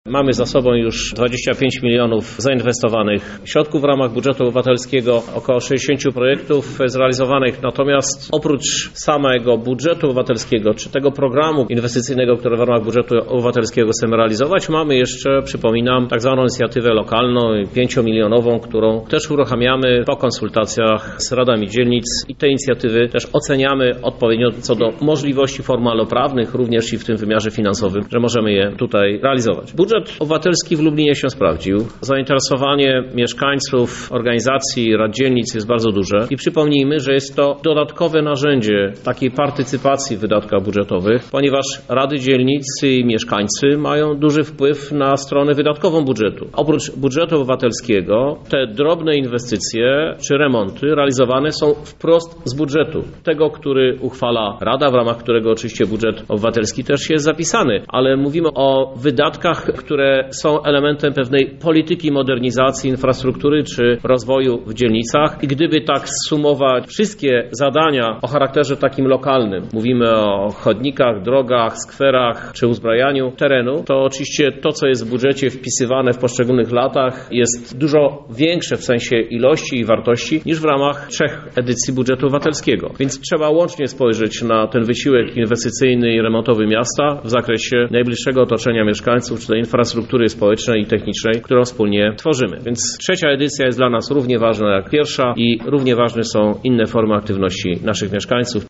O znaczeniu budżetu dla miasta mówi prezydent Krzysztof Żuk